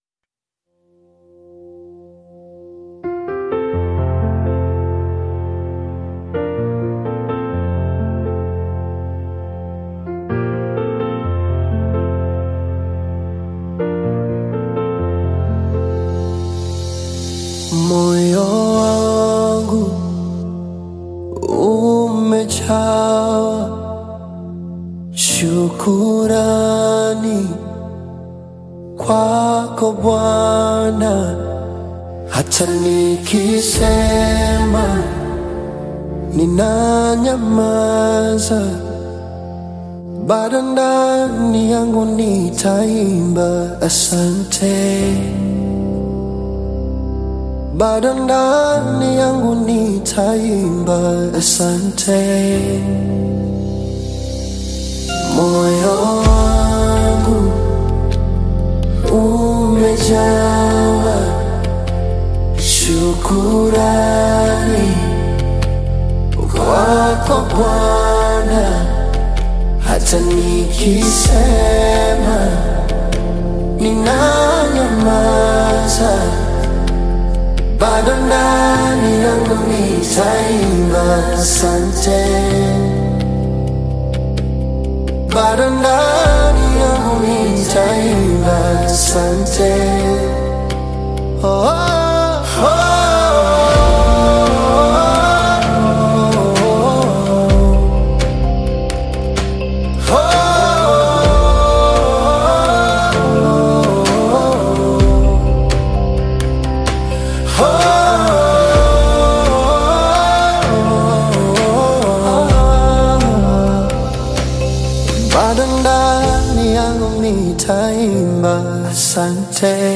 AudioGospel
soulful Gospel/Afro-Pop single